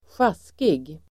Uttal: [²sj'as:kig]